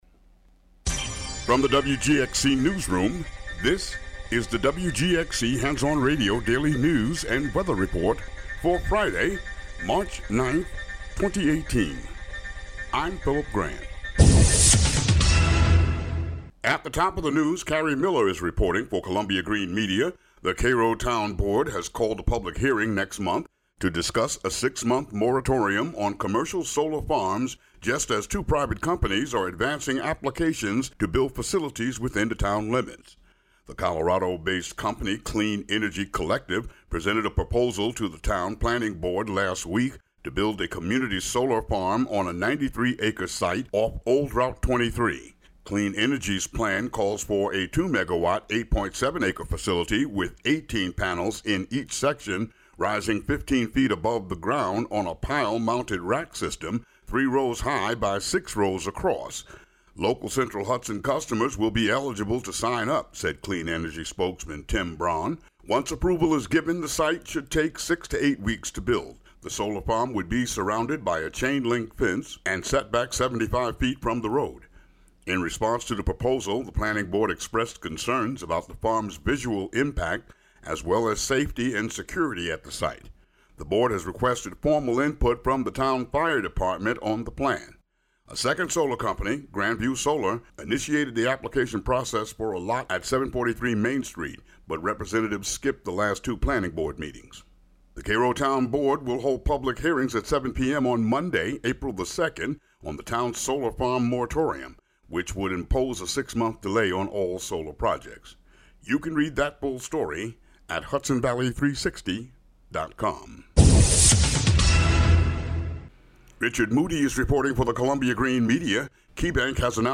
News about the WGXC listening area